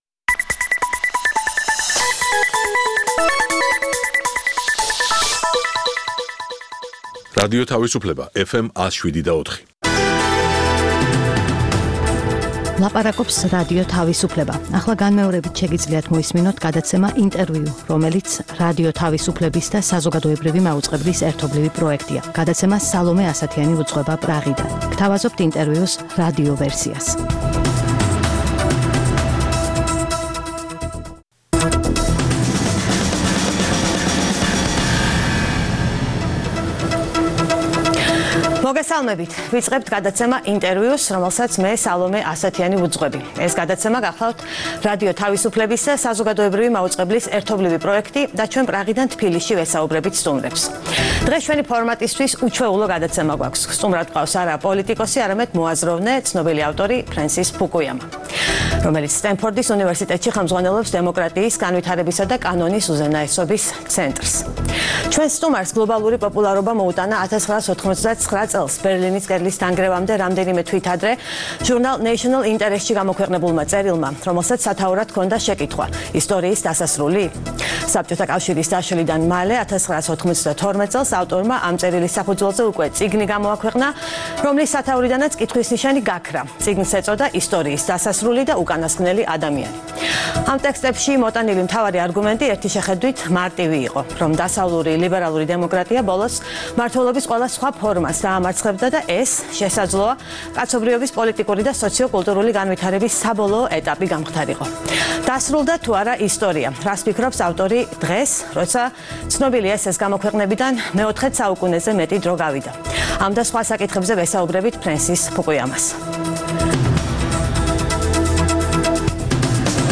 ყოველკვირეული გადაცემა „ინტერVIEW“ არის რადიო თავისუფლებისა და საზოგადოებრივი მაუწყებლის ერთობლივი პროექტი. მასში მონაწილეობისთვის ვიწვევთ ყველას, ვინც გავლენას ახდენს საქართველოს პოლიტიკურ პროცესებზე. „ინტერview“ არის პრაღა-თბილისის ტელეხიდი